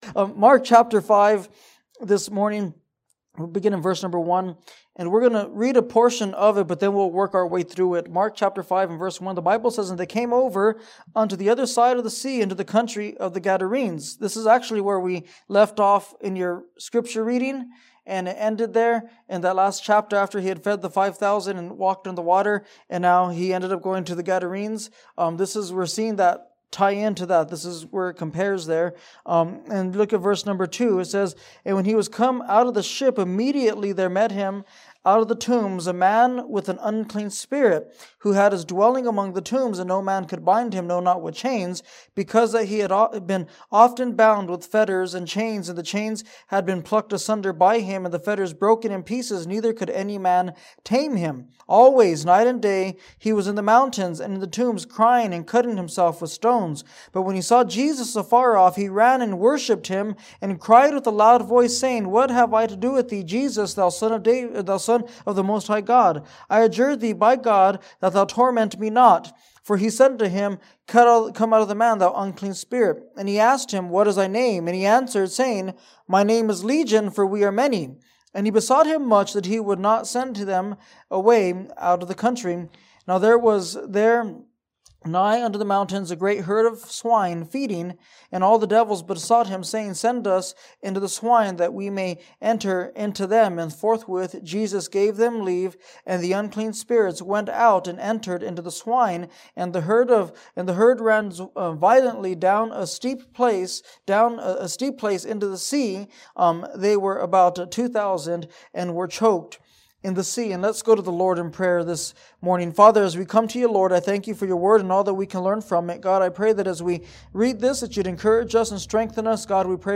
From Series: "2017 Missions Conference"